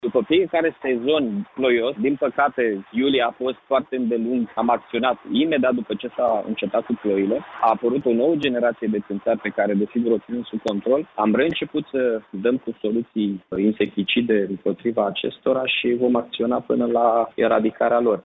La rândul său, primarul Iaşului, Mihai Chirica, a declarat pentru postul nostru de radio, că în muncipiul reşedinţă de judeţ există un program de dezinsecţie, iar acesta este respectat cu stricteţe: